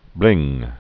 (blĭng) or bling-bling (blĭngblĭng) Slang